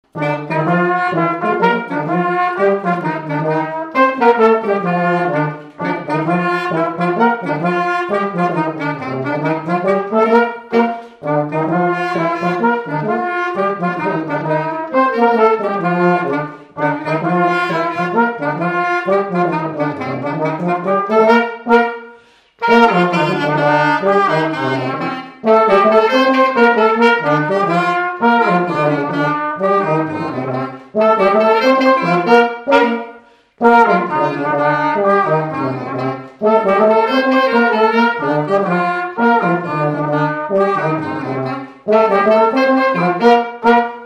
Mazurka
Chants brefs - A danser
Résumé instrumental
danse : mazurka
Pièce musicale inédite